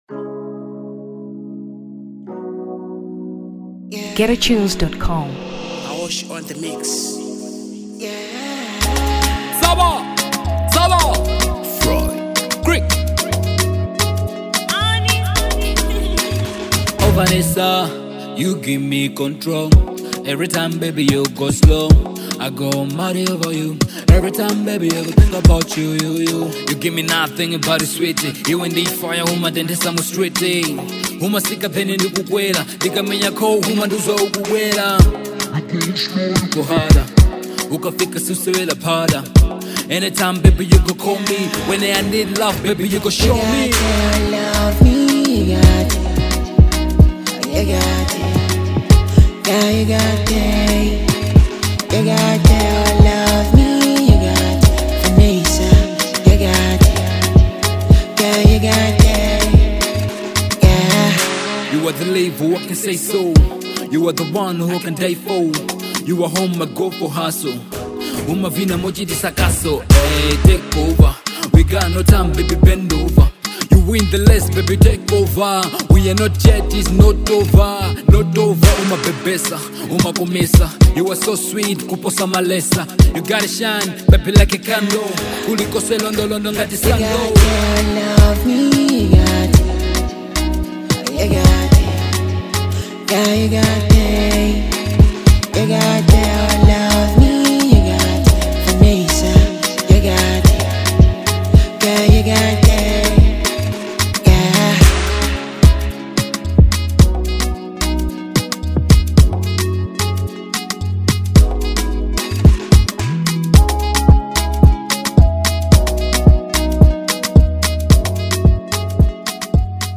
Afrobeats 2023 Malawi